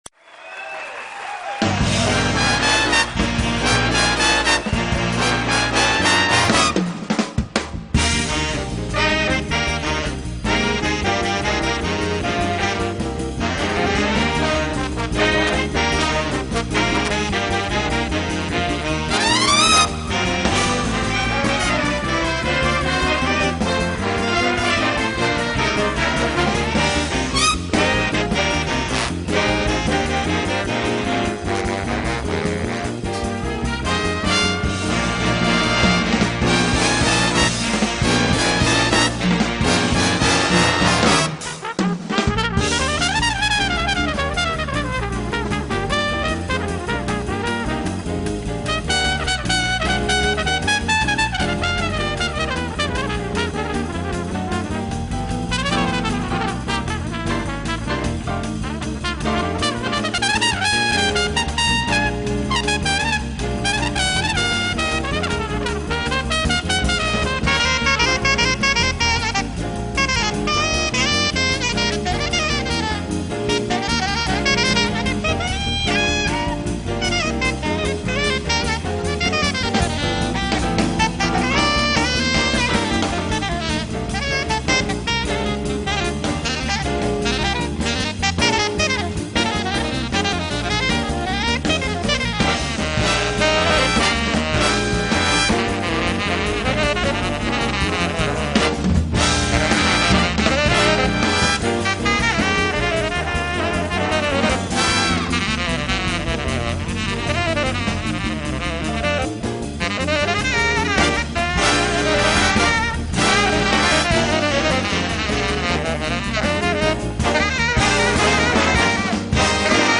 Live, Big Band Version